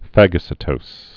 (făgə-sĭ-tōs, -tōz, -sītōs, -tōz)